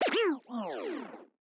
pegchamp/SFX/Scratch/Fadeout.ogg at alpha
Fadeout.ogg